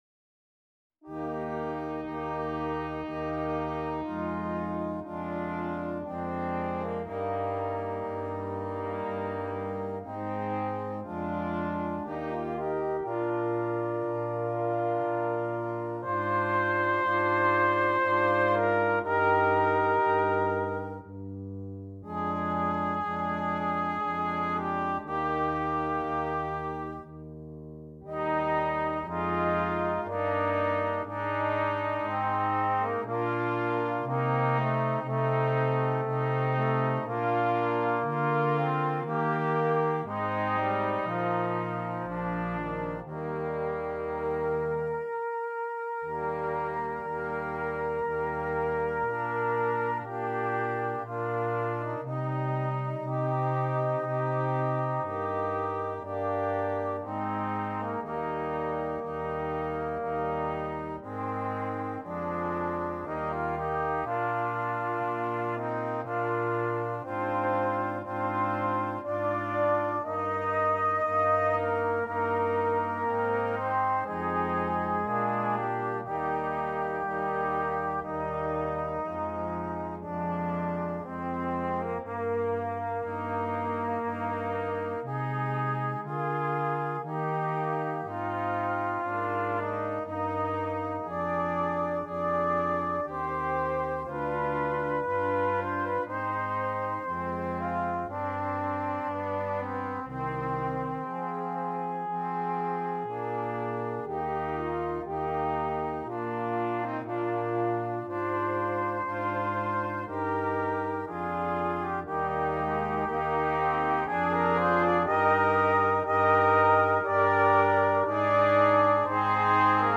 Gattung: Für Blechbläserquintett
Besetzung: Ensemblemusik für 5 Blechbläser